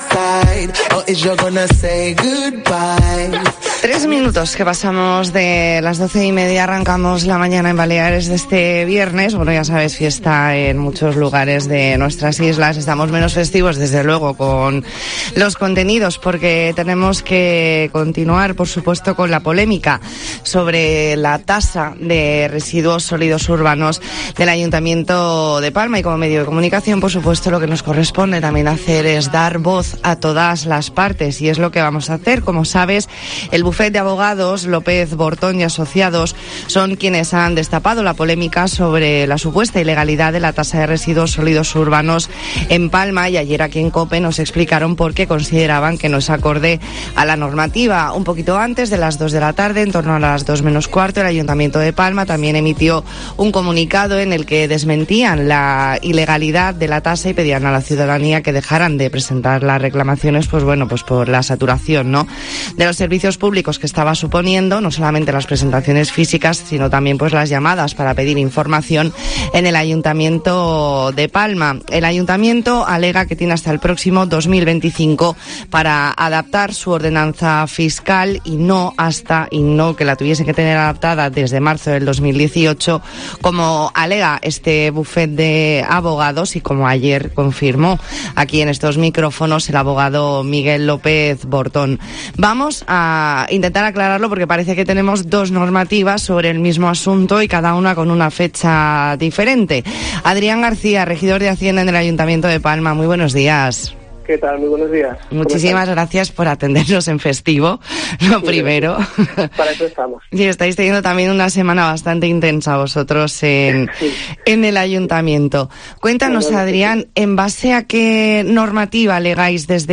Hablamos con Adrián García, regidor de Hacienda en el Ayuntamiento de Palma . E ntrevista en La Mañana en COPE Más Mallorca, viernes 24 de junio de 2022.